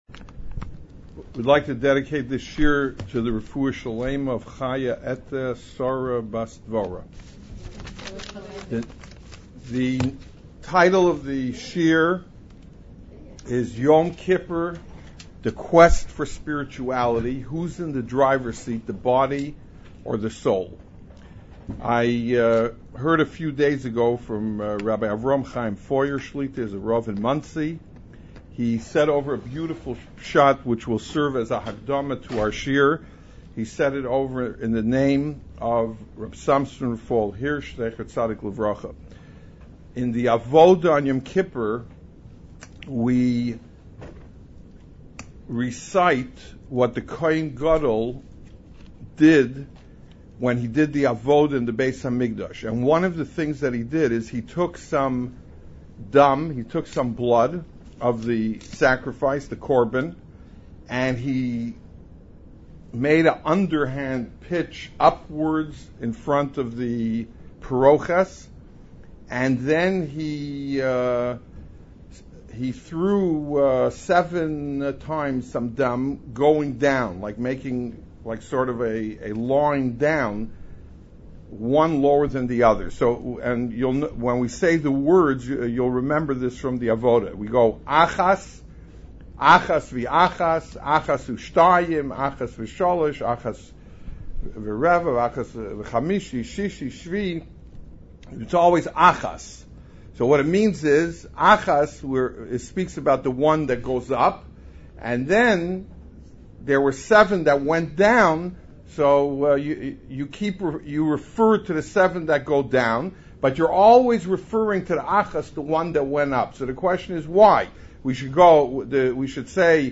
Yom Kippur: The Quest for Spirituality. Who's in the driver's seat, body or soul?! Recorded live at OU World Headquarters NY, NY September 19, 2007.